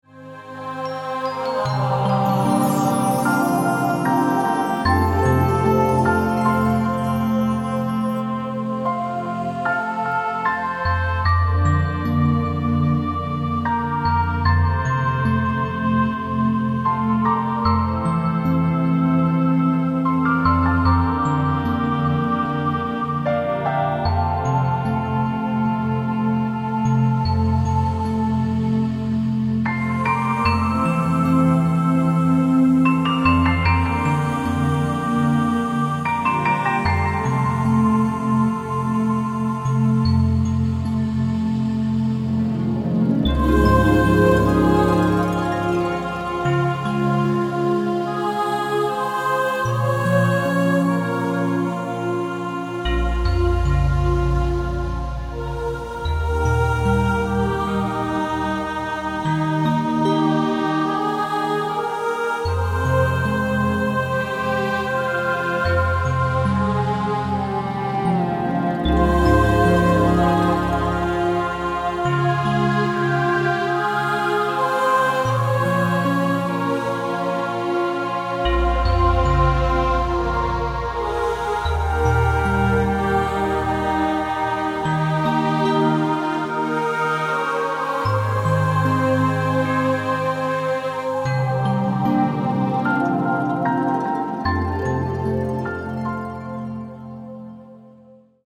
Musik zum Meditieren, Träumen und Entspannen.